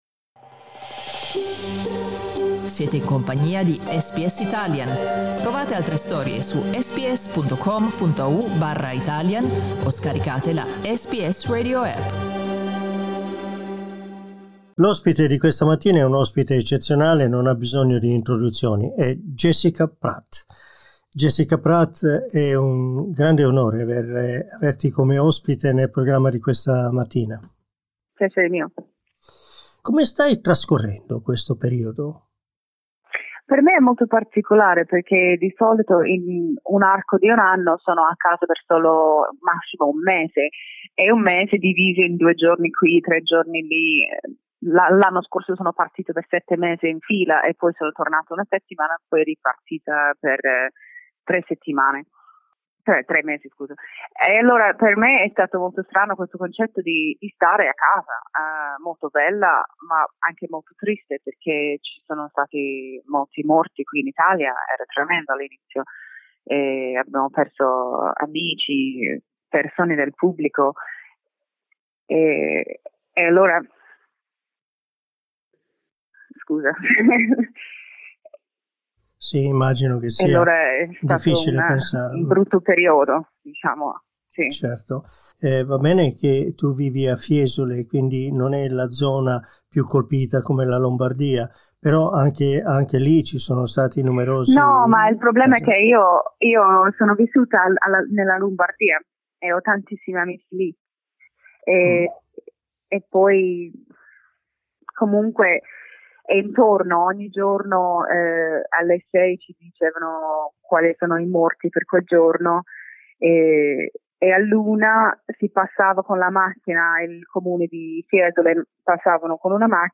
In questa intervista ci racconta che con rammarico ha dovuto abbandonare quasi tutti gli impegni del 2020 non potendoli trasferire al 2021 in quanto era già quasi tutto prenotato.